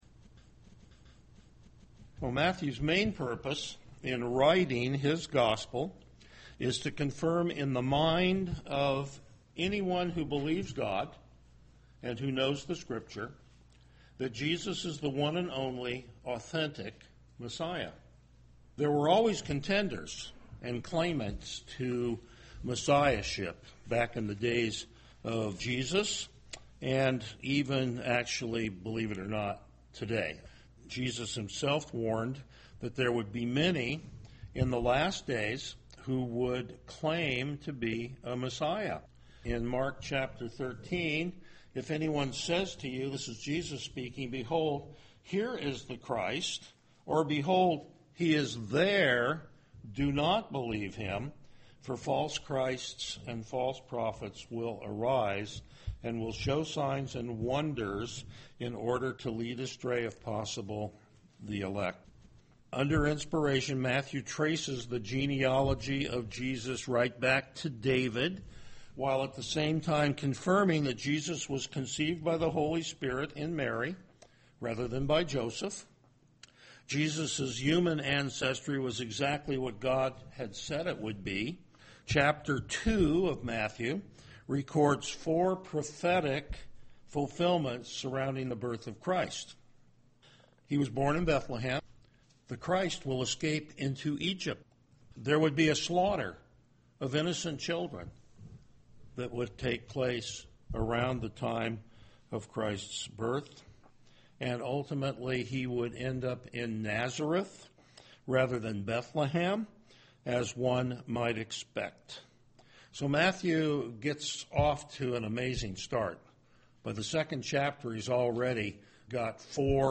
Passage: Matthew 2:13-23 Service Type: Morning Worship
Topics: Verse By Verse Exposition